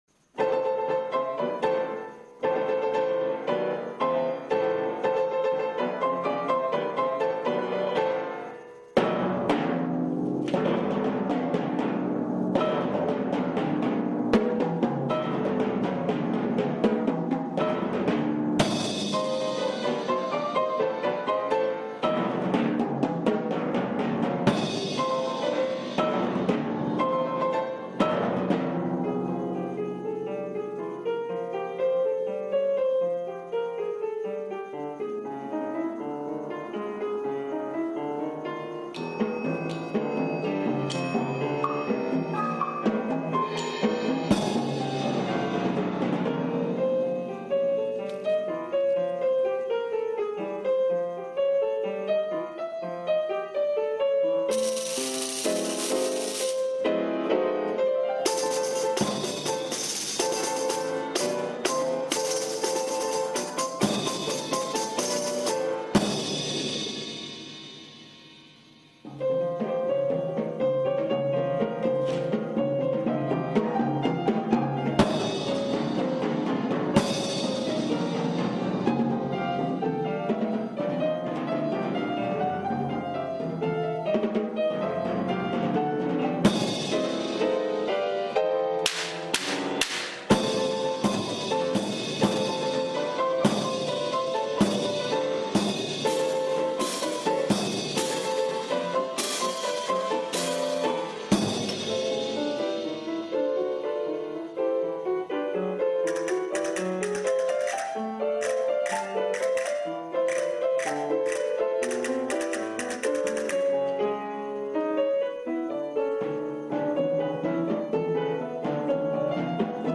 percusion.mp4